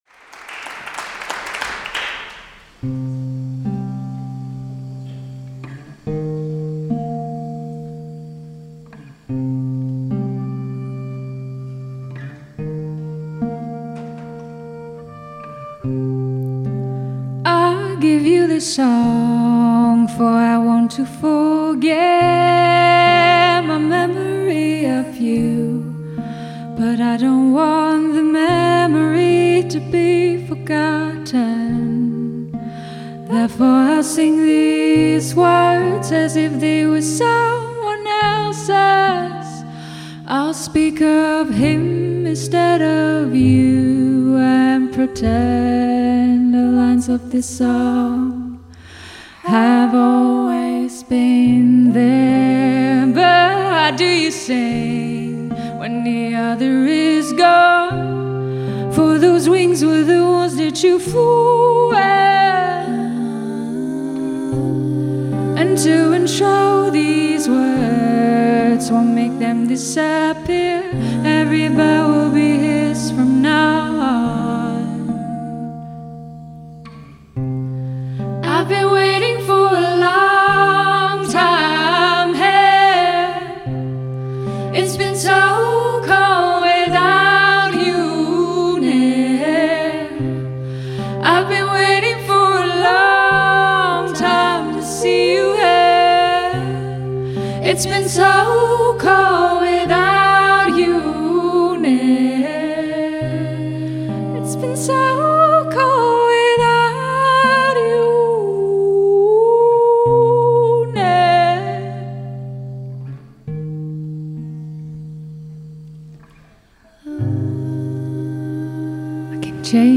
Singer-Songwriter
festival in Rotterdam
sumptuous languor and spellbinding simplicity.